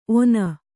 ♪ ona